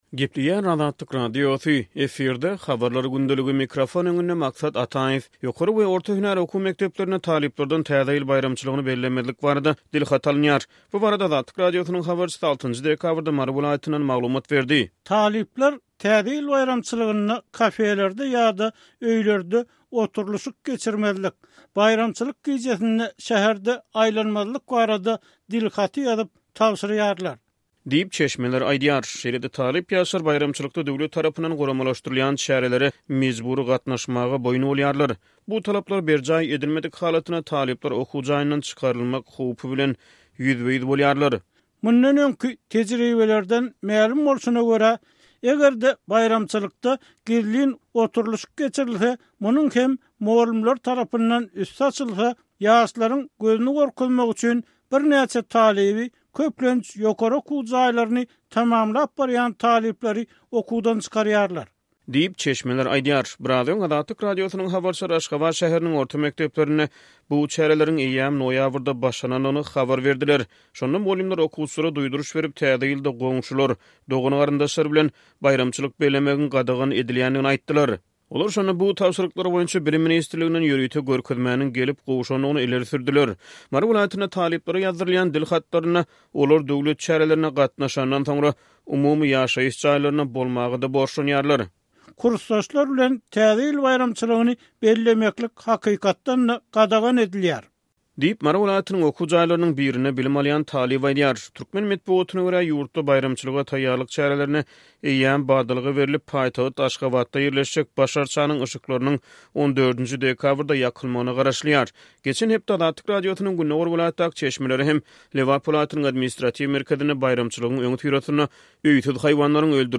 Ýokary we orta hünär okuw mekdeplerinde talyplardan Täze ýyl baýramçylygyny bellemezlik barada dilhaty alynýar. Bu barada Azatlyk Radiosynyň habarçysy 6-njy dekabrda Mary welaýatyndan maglumat berdi.